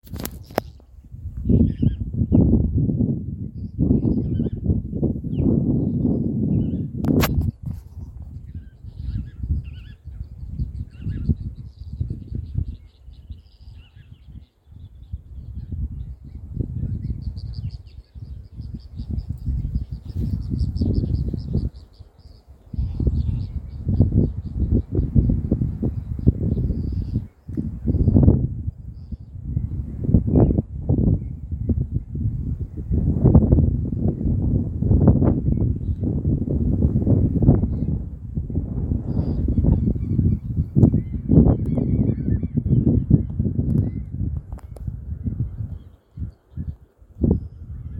Chilean Flamingo (Phoenicopterus chilensis)
Sex: Both
Life Stage: Several
Location or protected area: Tafi Viejo. Cadillal
Condition: Wild
Certainty: Recorded vocal